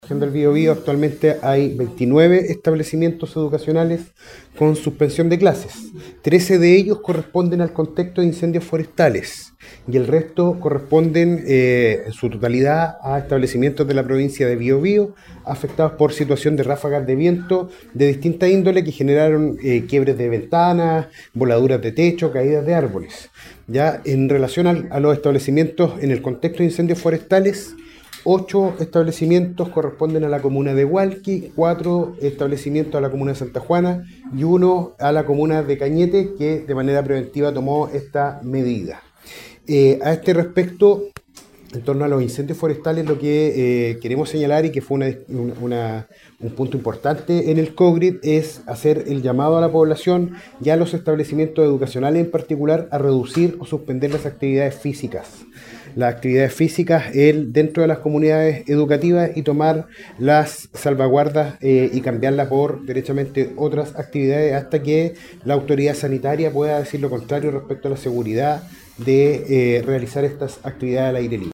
Otras de las decisiones tomadas fue la suspensión de clases en 29 establecimientos educacionales de Hualqui, Santa Juana, Antuco, Quilaco, Santa Bárbara y Tucapel. El seremi de Educación, Carlos Benedetti, detalló esta medida.